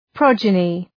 Προφορά
{‘prɒdʒənı}